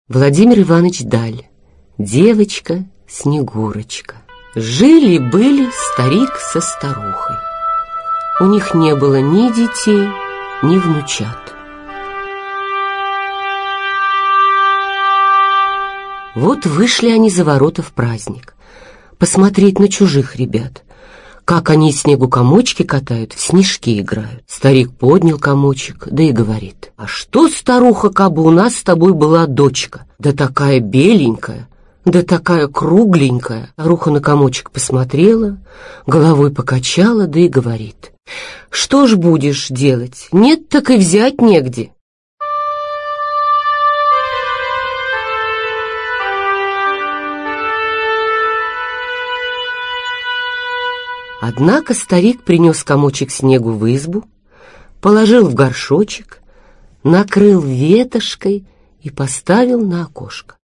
Аудиокнига Серая шейка. Зимние сказки русских писателей | Библиотека аудиокниг
Зимние сказки русских писателей Автор Дмитрий Мамин-Сибиряк Читает аудиокнигу Николай Дроздов.